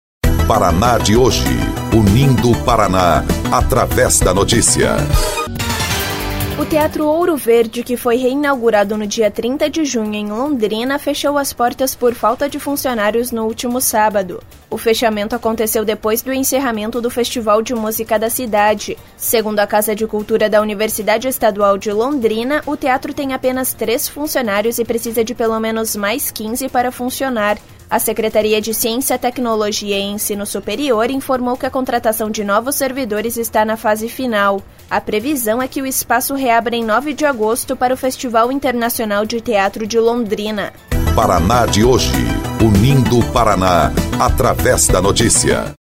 BOLETIM – Teatro Ouro Verde, em Londrina, fecha por falta de funcionários